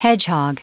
Prononciation du mot hedgehog en anglais (fichier audio)
Prononciation du mot : hedgehog